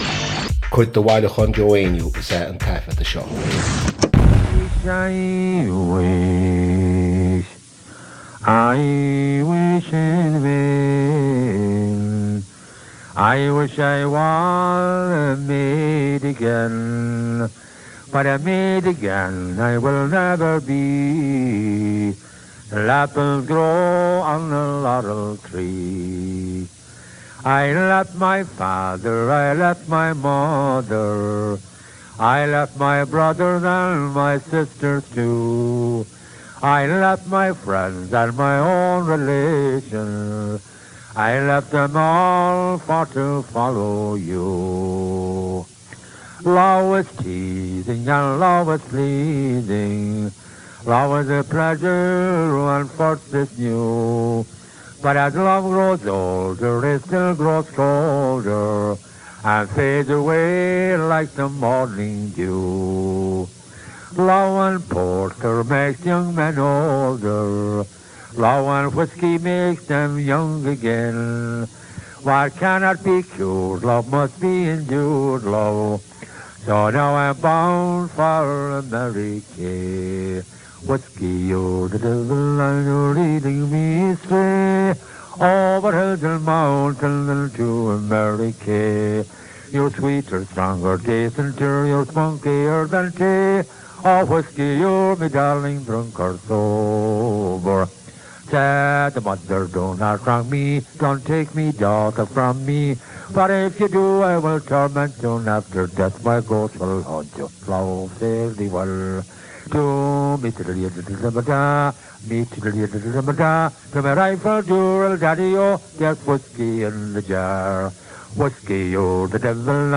• Catagóir (Category): song.
• Ainm an té a thug (Name of Informant): Joe Heaney.
• Suíomh an taifeadta (Recording Location): Plowshares Coffee House, San Francisco, United States of America.
• Ocáid an taifeadta (Recording Occasion): Concert.
On this occasion, Joe created a medley of ‘Love is Teasing’ followed by ‘Whiskey, You’re the Devil’.